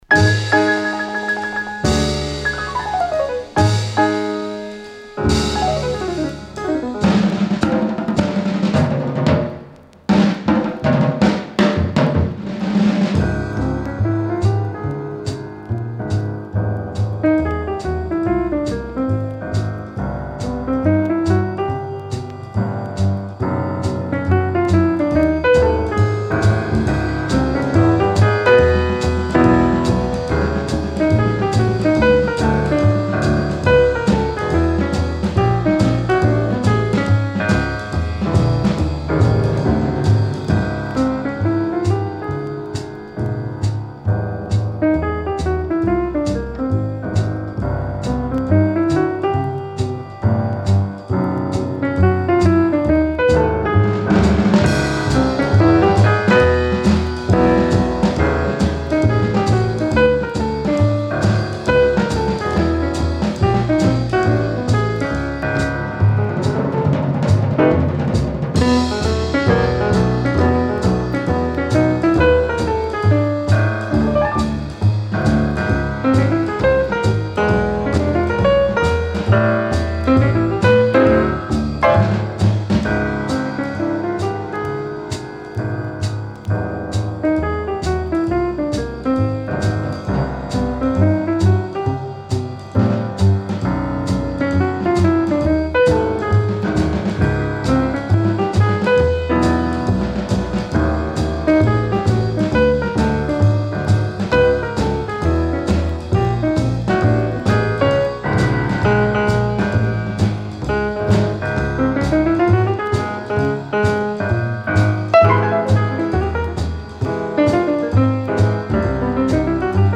mono pressing
at Van Gelder Studio, Hackensack, New Jersey